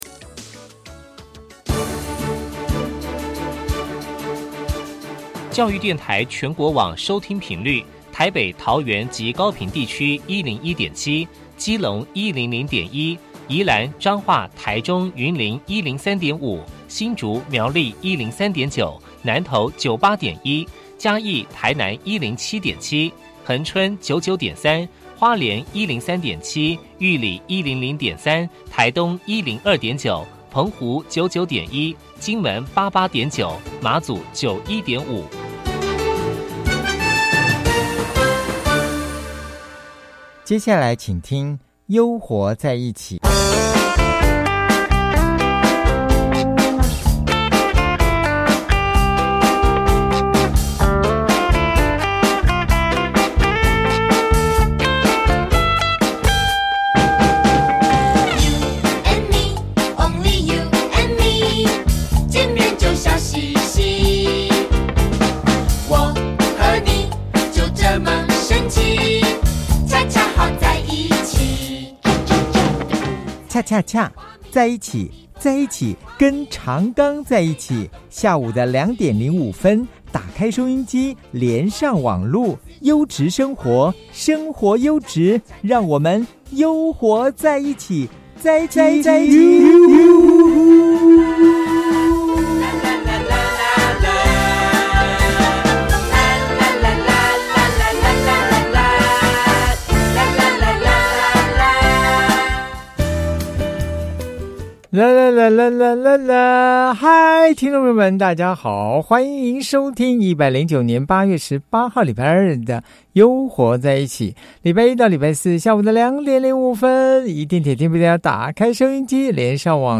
展覽廣播專訪